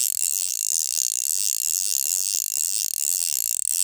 Reel_loop_1.ogg